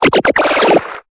Cri de Mucuscule dans Pokémon Épée et Bouclier.